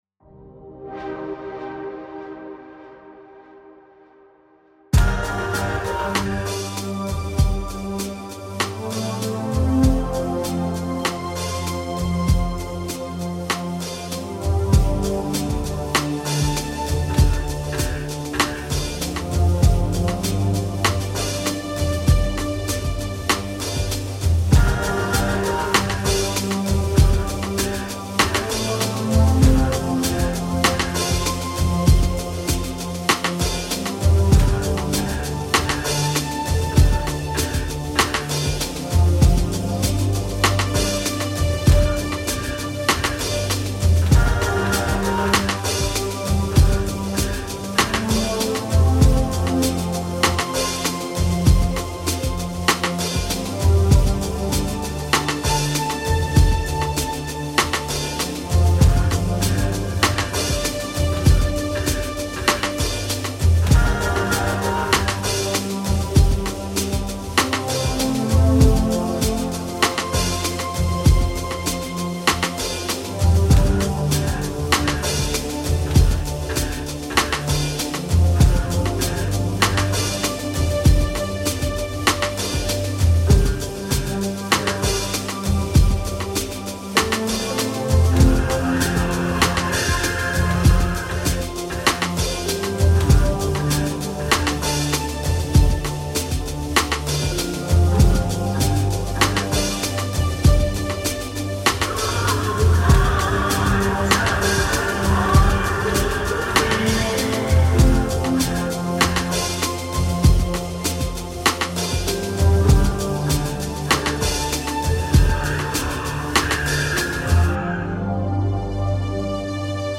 with a rhythm reminiscent of an early 90s house track.